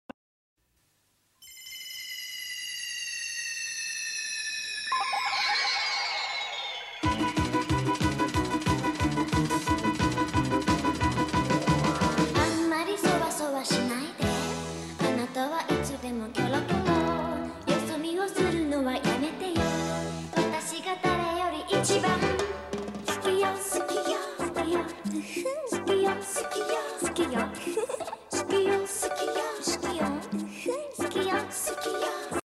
ew ew ew ew sound effects free download